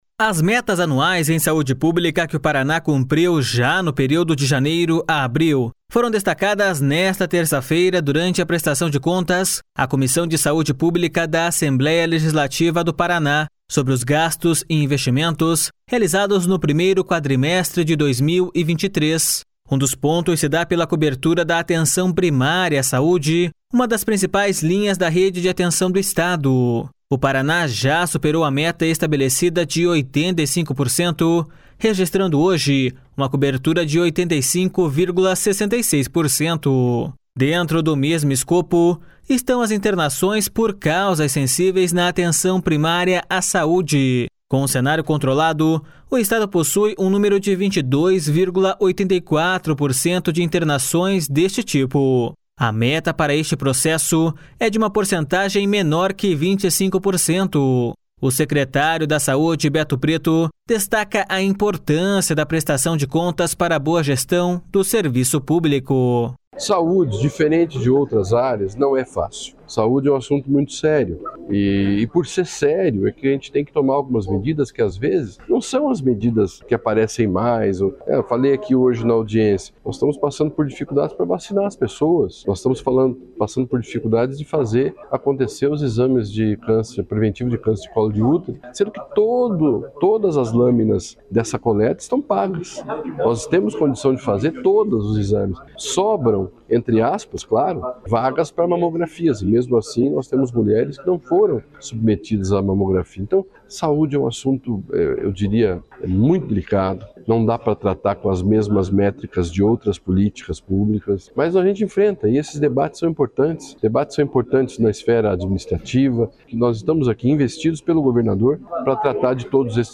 O secretário da Saúde, Beto Preto, destaca a importância da prestação de contas para a boa gestão do serviço público.// SONORA BETO PRETO.//